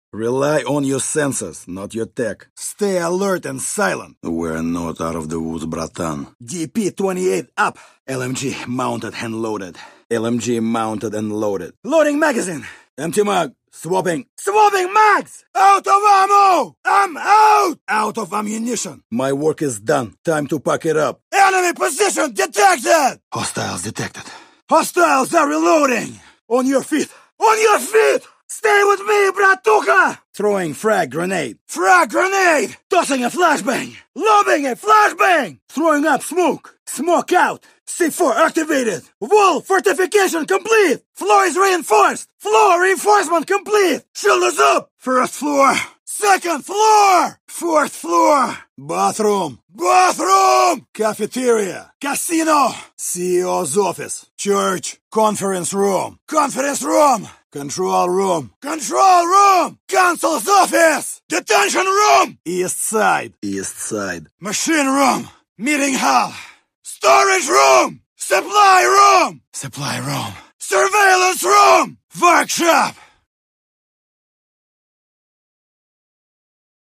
tachanka voice line collection